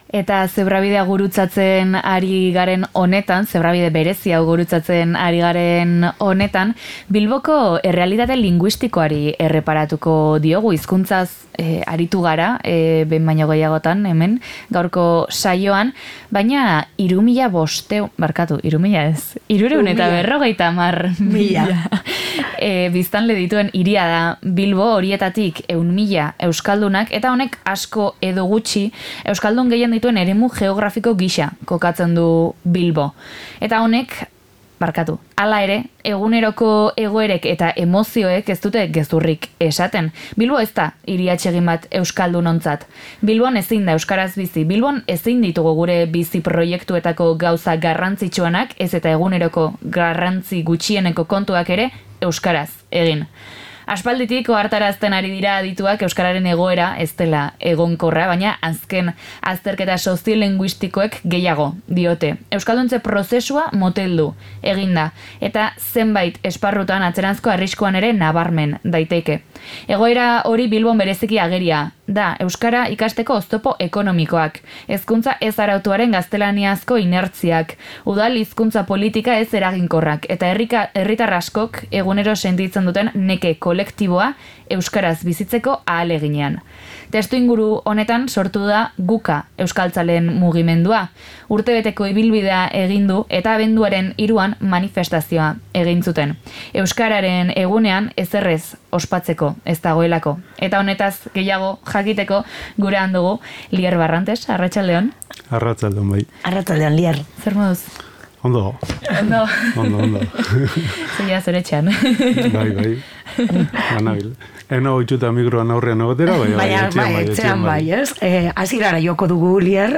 Horregatik gaur Zebrabidea Bilbo Hiria irratiko estudiotik egin dugu.